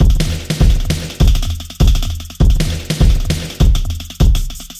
描述：2小节慢循环，100bpm。砰砰的踢踏声，紧张地响着帽子和小鼓的滚动。
Tag: 100 bpm Weird Loops Drum Loops 828.81 KB wav Key : E